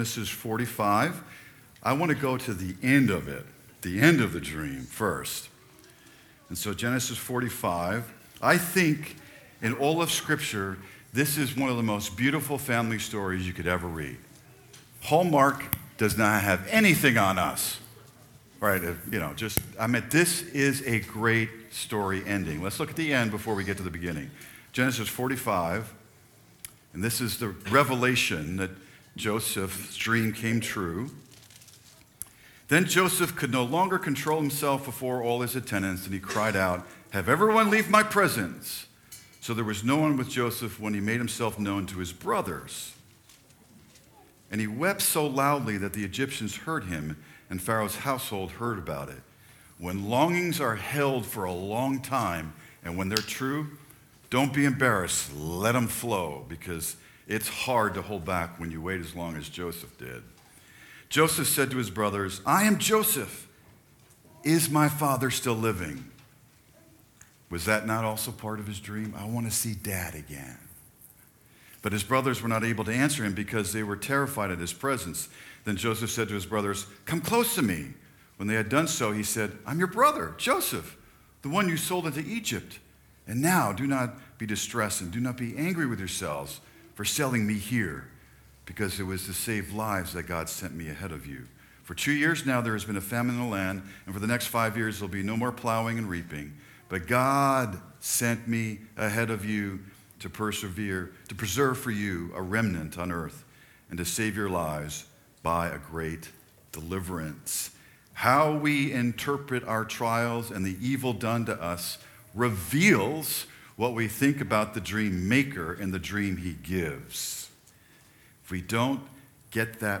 Sermons | New Buffalo Alliance Church